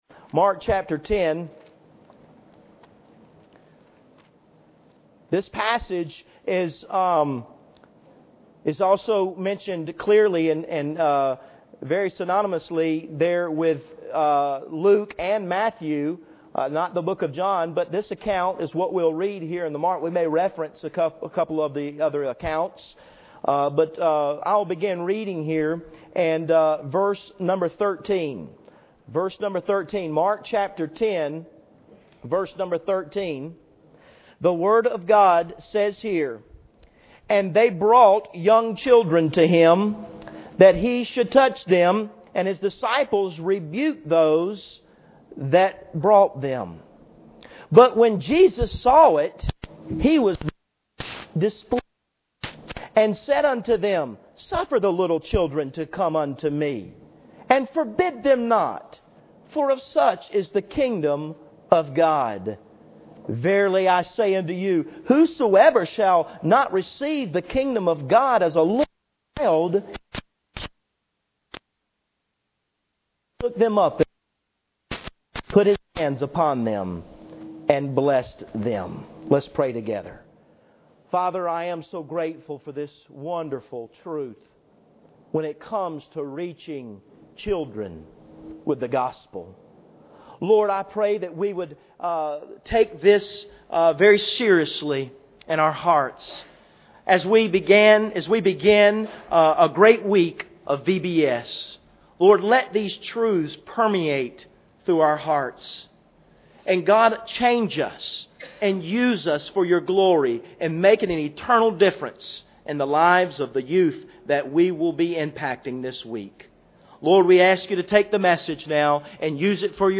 Mark 10:13-16 Service Type: Sunday Morning Bible Text